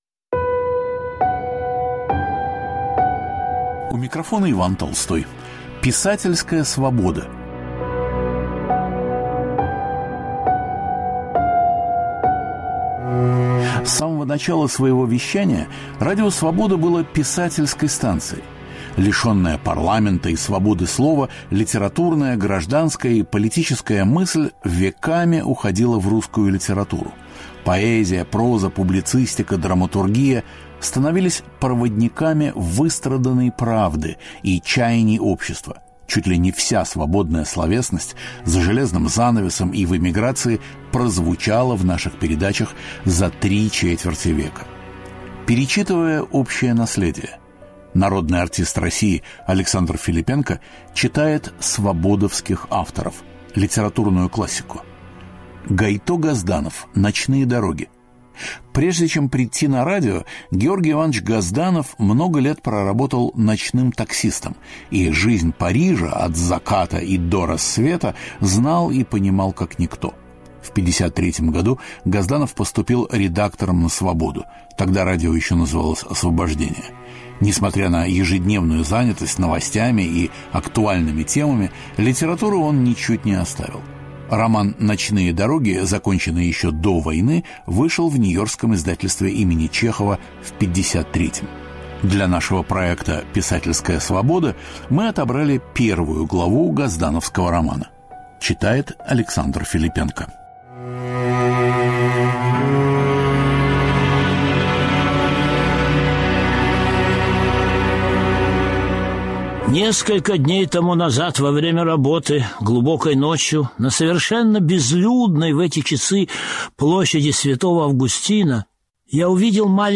Писательская свобода. Александр Филиппенко читает фрагмент из романа Гайто Газданова «Ночные дороги»
В исполнении Александра Филиппенко звучит первая глава его романа «Ночные дороги», который вышел в тот самый год, когда Радио Свобода начало свое вещание - в 1953-м.